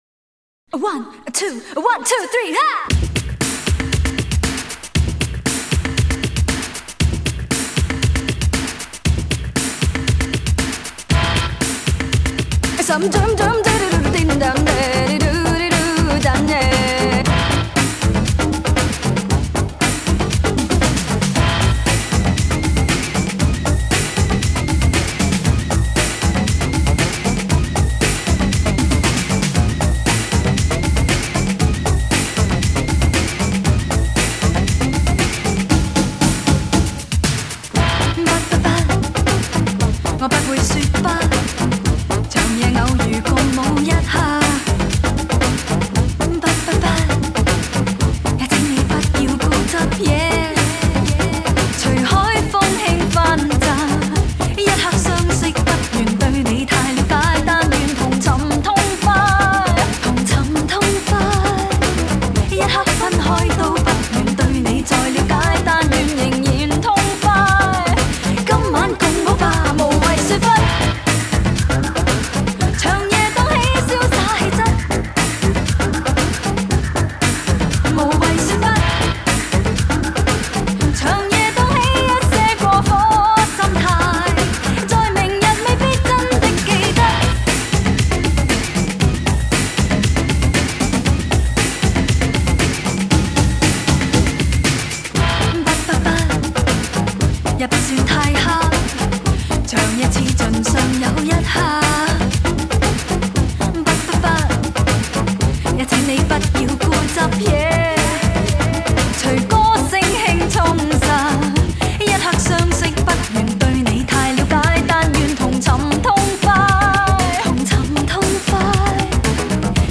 发一个她的快歌